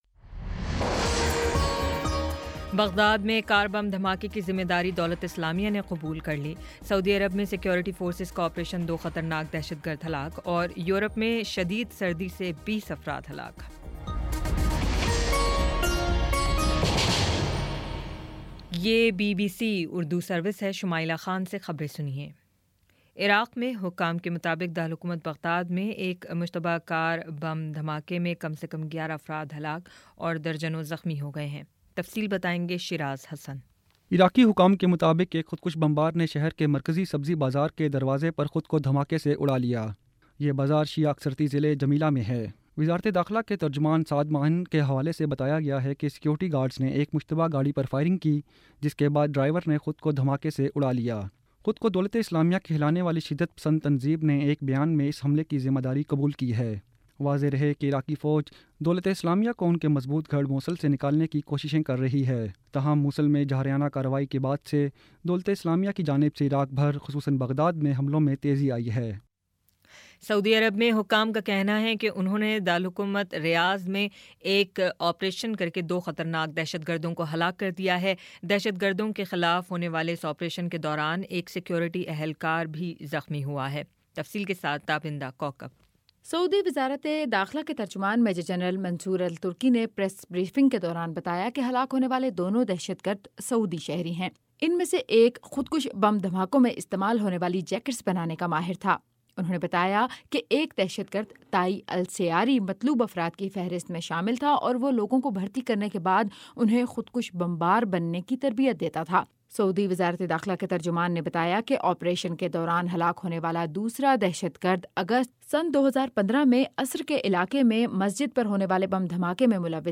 جنوری 08 : شام پانچ بجے کا نیوز بُلیٹن